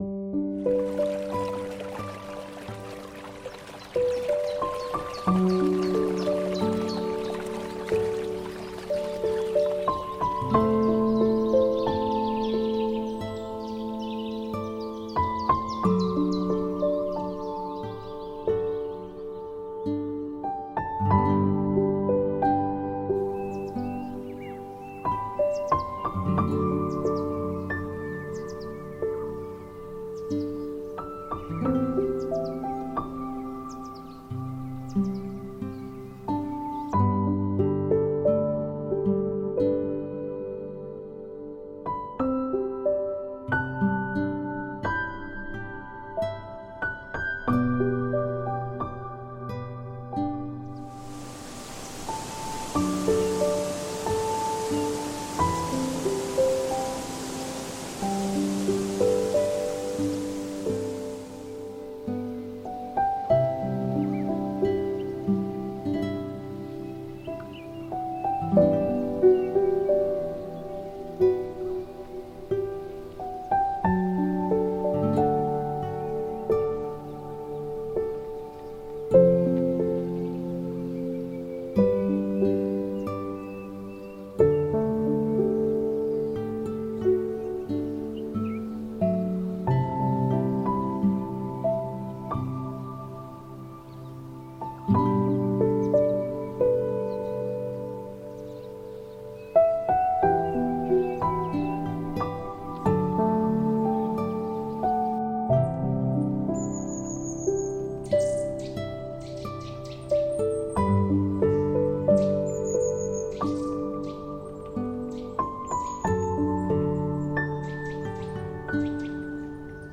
Slowenien-Wälder-Flüsse: Friedliche Ruhe in entspannenden Wäldern & Flüssen